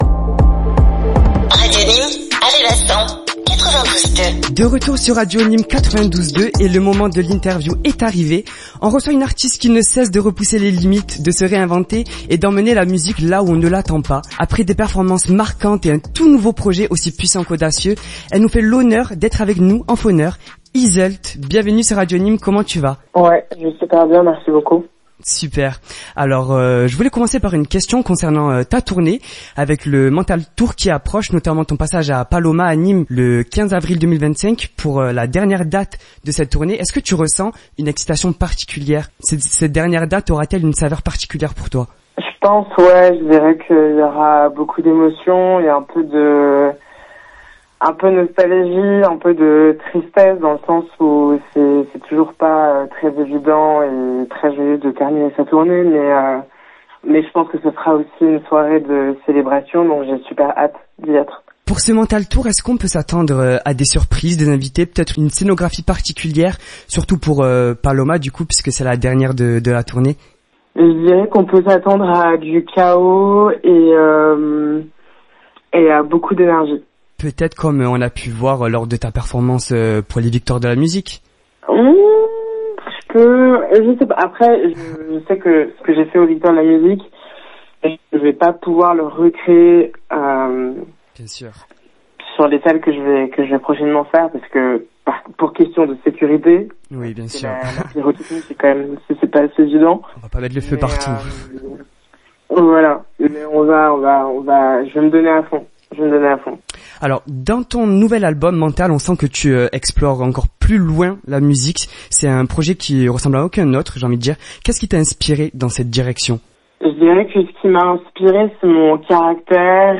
Radio Nîmes a l'honneur de recevoir Yseult en phoner.